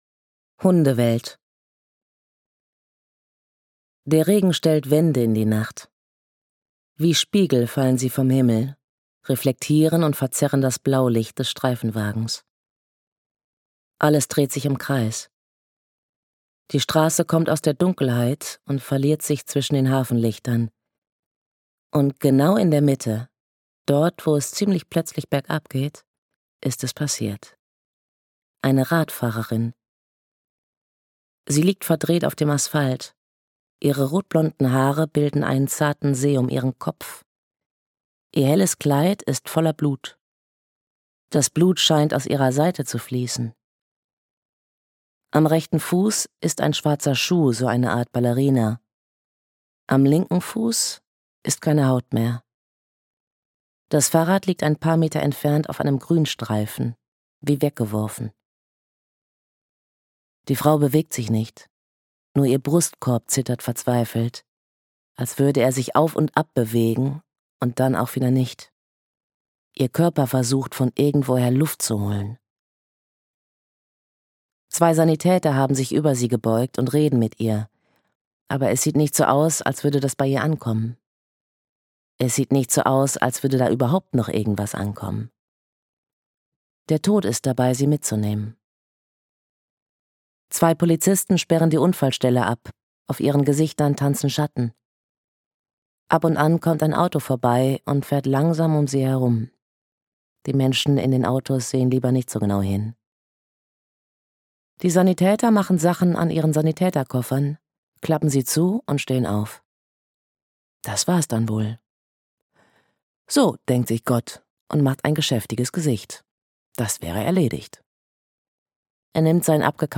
Beton Rouge (Chastity-Riley-Reihe 7) - Simone Buchholz - Hörbuch